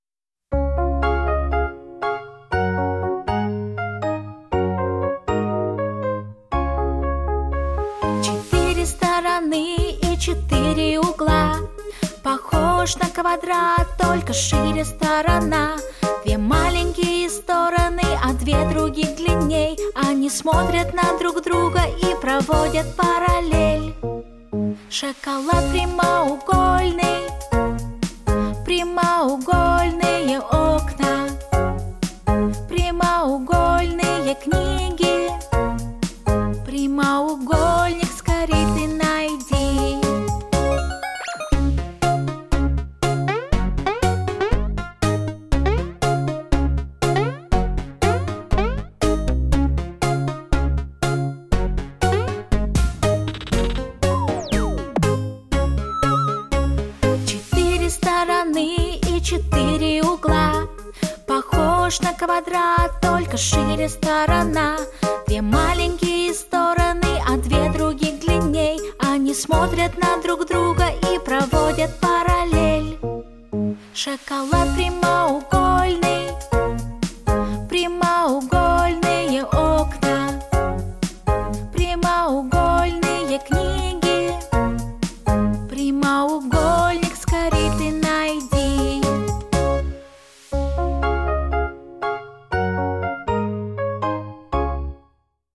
• Жанр: Детские песни
малышковые